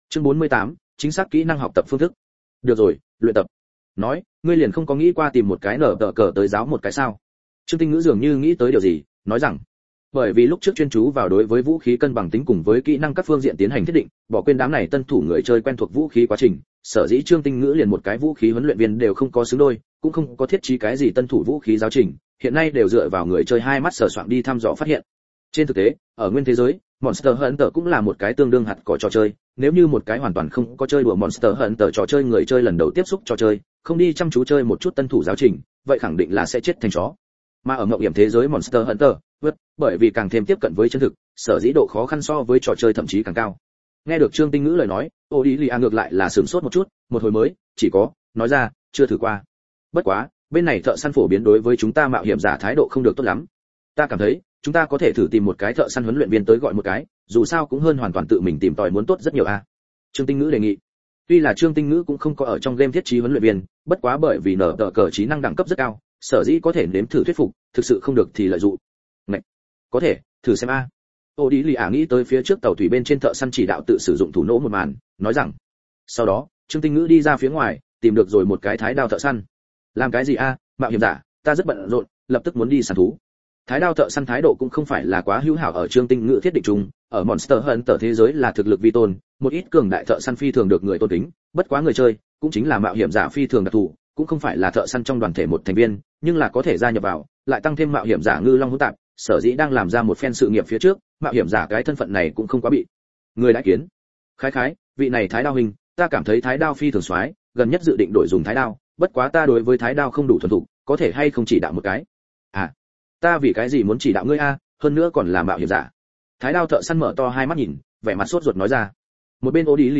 Thật Có Lỗi! Các Ngươi Chơi Võng Du Thế Giới Liền Là Ta Sáng Tạo Audio - Nghe đọc Truyện Audio Online Hay Trên AUDIO TRUYỆN FULL